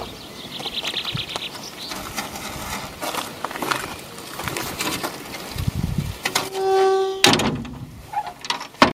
Bruit portail entree (2018)